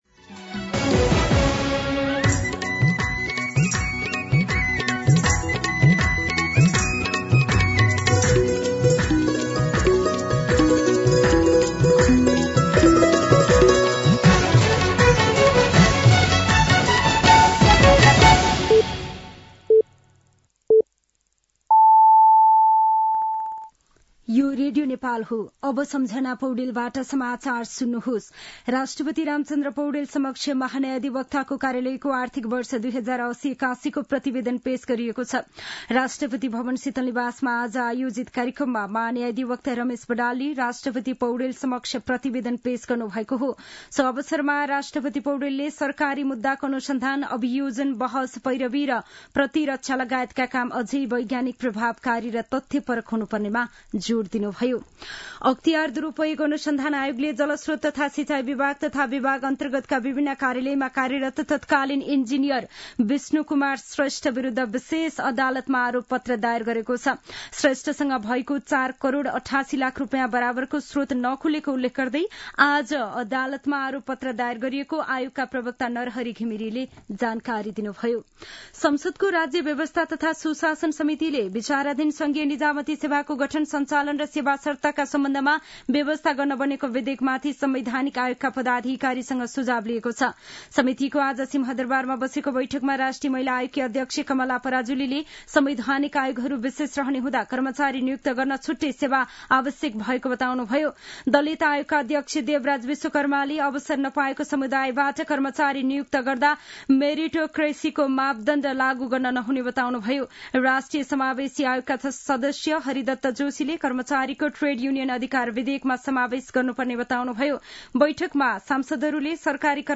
दिउँसो ४ बजेको नेपाली समाचार : २९ पुष , २०८१
4-pm-nepali-news-5.mp3